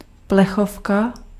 Ääntäminen
IPA: [bi.dɔ̃]